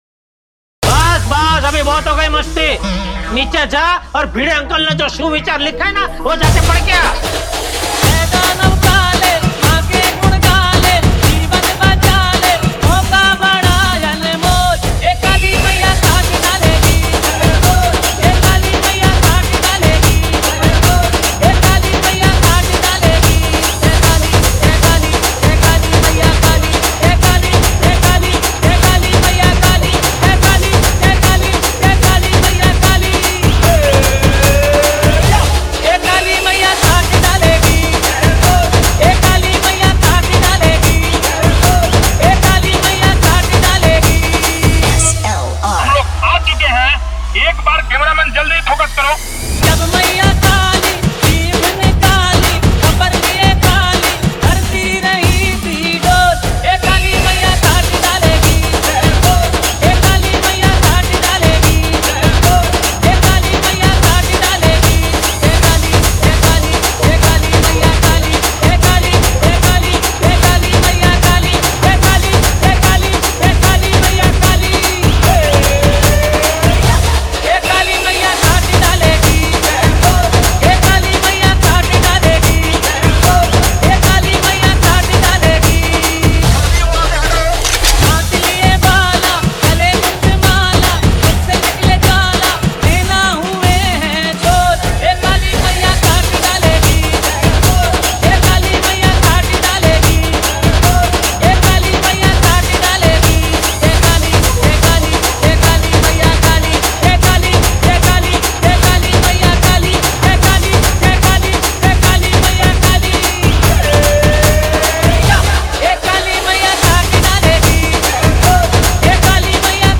- bhakti dj song